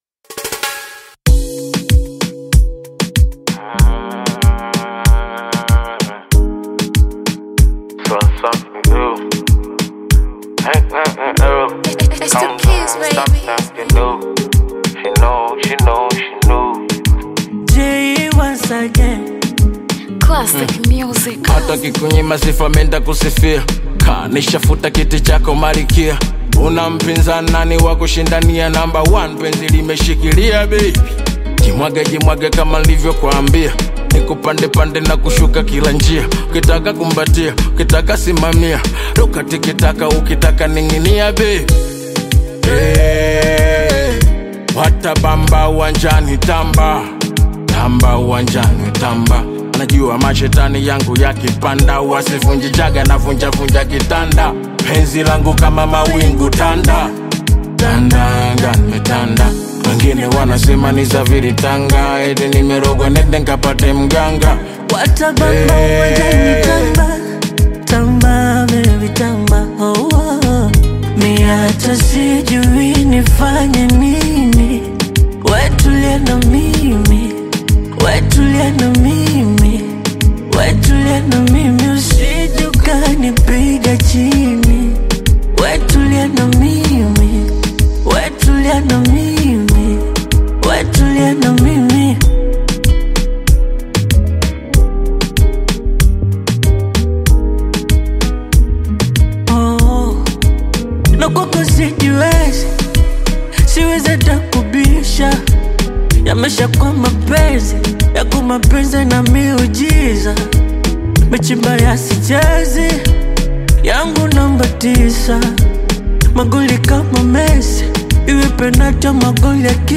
soulful Bongo Flava/Afro-Pop collaboration
Genre: Bongo Flava